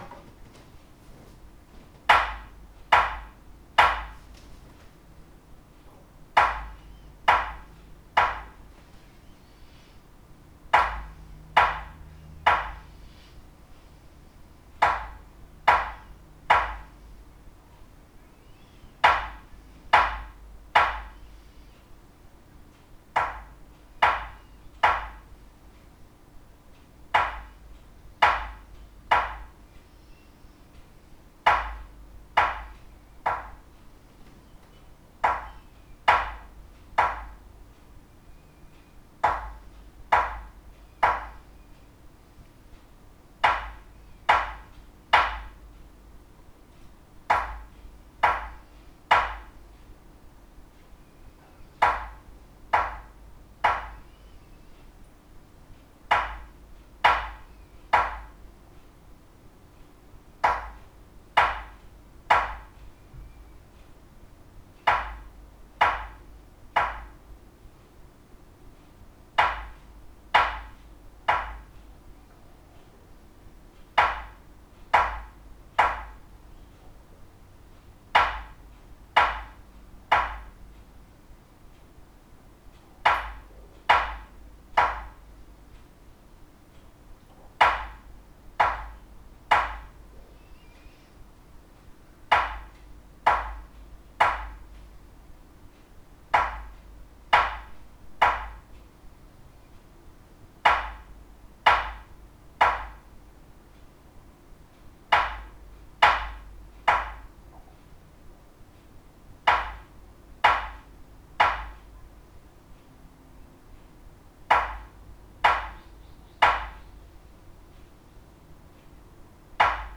..This "Dead Tapper" will eliminate much of the high overtones which really helps us hear the lowest overtone, which is what we are listening and adjusting our violin parts from...HIGHLY recommended for tap tone tuning!
<<<< CLICK BELOW >>>> photos, to hear (3) Minutes of: {3-Tap; Pause} recordings made with this Lead Shot filled spruce tap tool ....:
Recorded with Zoom Recorder and Lead filled spruce tapper.
leadtapperzoom_3min.wav